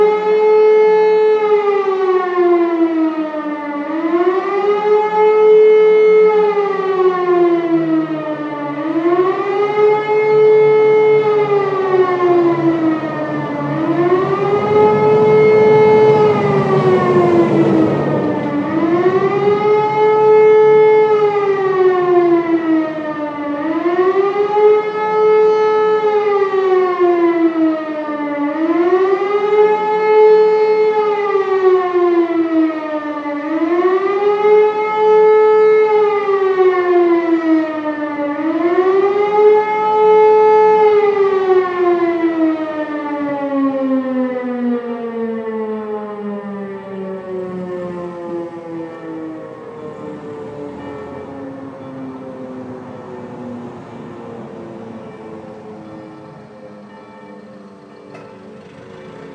Sirene in Zeilitzheim